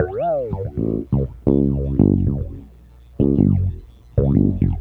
Bass Lick 35-02.wav